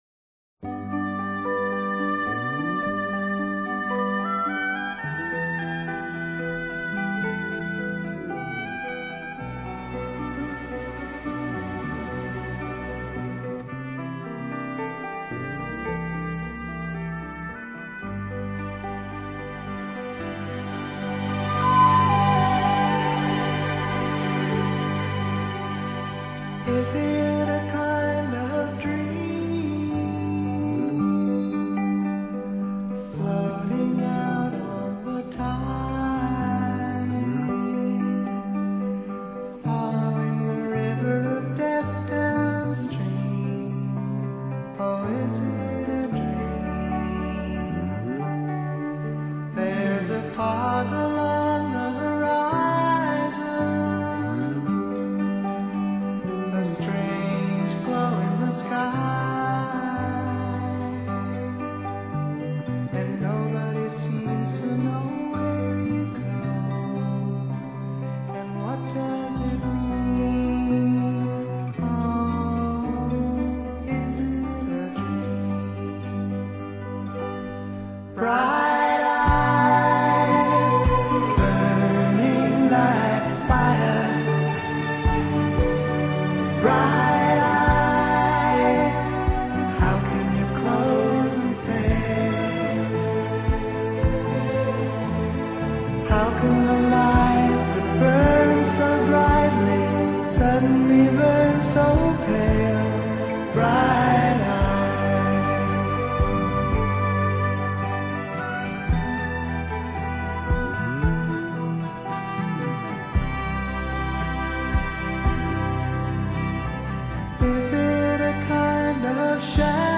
Lite Rock, Rock | MP3 320 Kbps CBR | 00:39:31 min | 93 MB